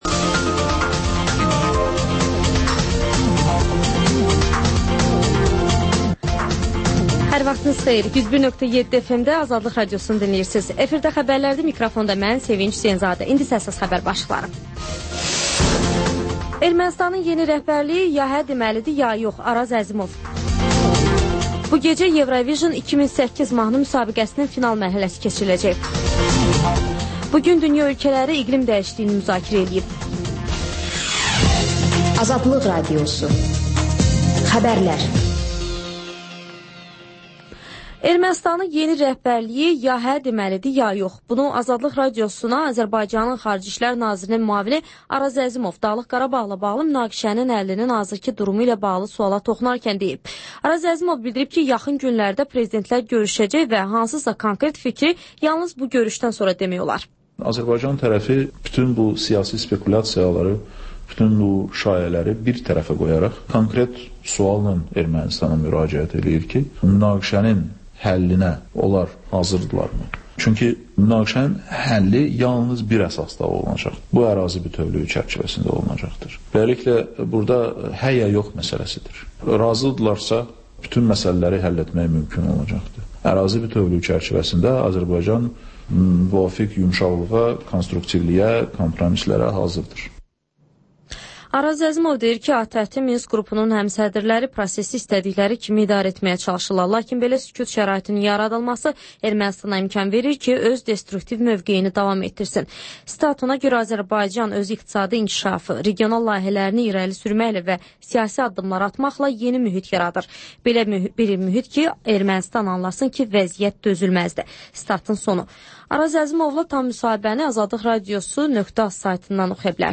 Xəbərlər və XÜSUSİ REPORTAJ: Ölkənin ictimai-siyasi həyatına dair müxbir araşdırmaları